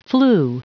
Prononciation du mot flu en anglais (fichier audio)